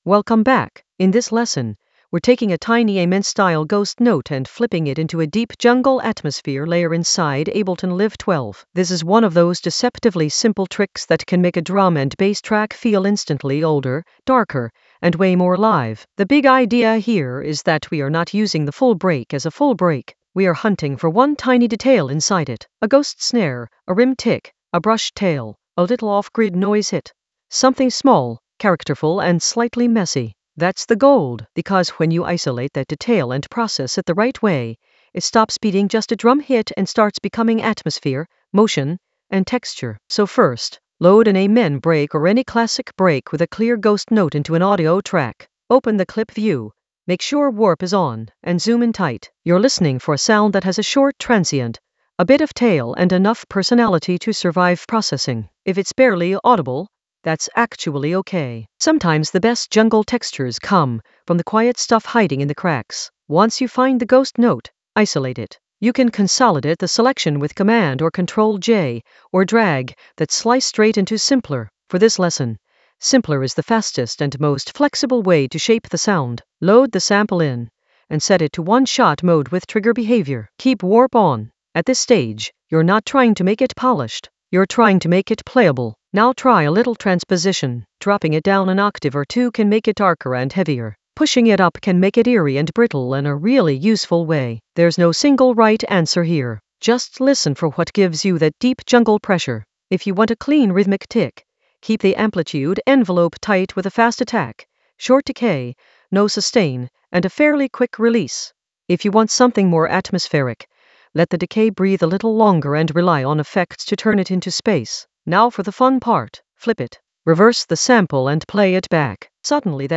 Narrated lesson audio
The voice track includes the tutorial plus extra teacher commentary.